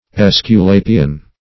Esculapian \Es`cu*la"pi*an\, n.